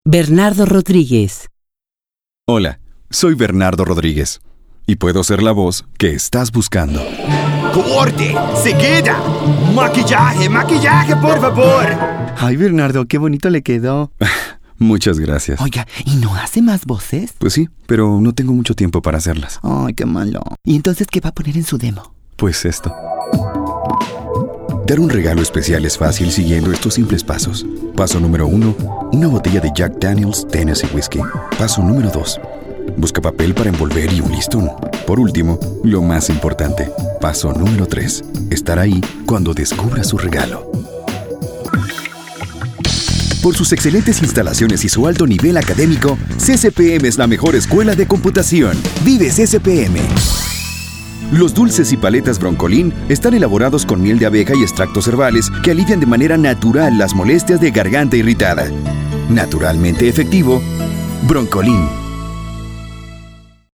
Voz institucional, comercial y caracterización...
Sprechprobe: Werbung (Muttersprache):